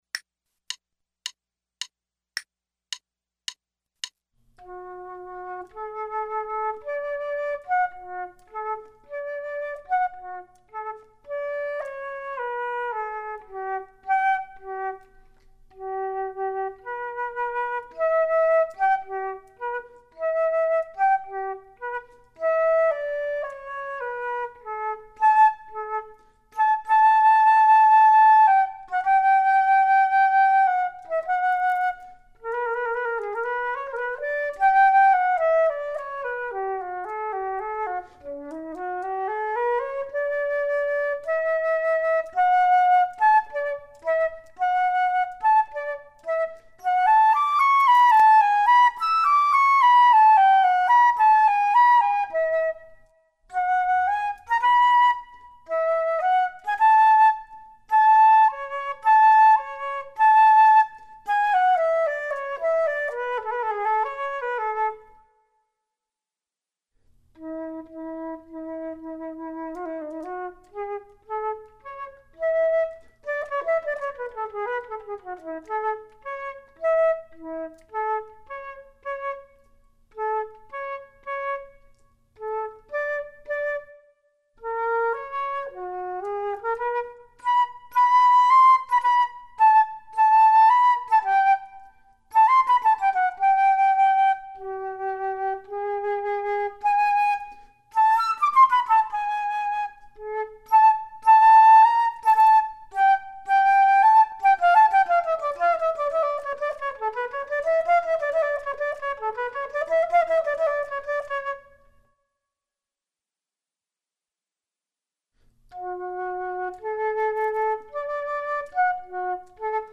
Flute 2 Only:
The metronome setting for this performance is quarter note = 108.